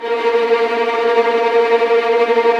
Index of /90_sSampleCDs/Roland LCDP13 String Sections/STR_Violins Trem/STR_Vls Trem wh%